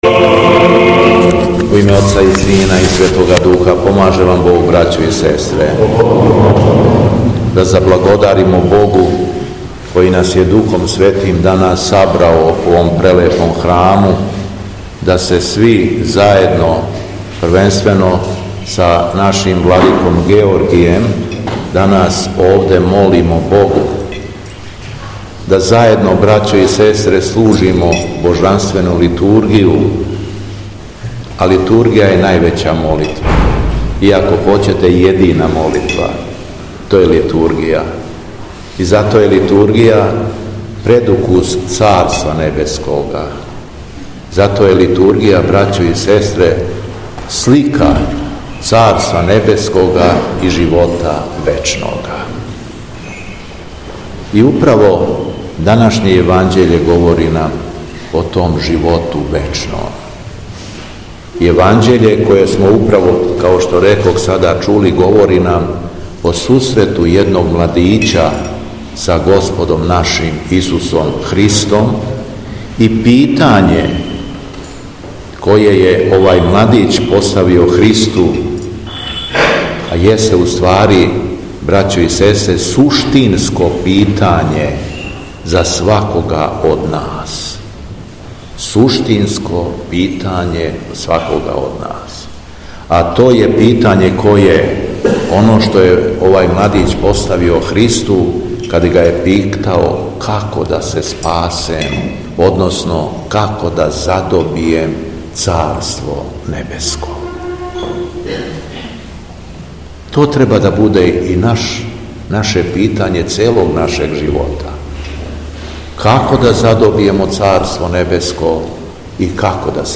Беседа Његовог Високопреосвештенства Митрополита шумадијског г. Јована
Надахнутом беседом Митрополит Јован је поучавао окупљене вернике: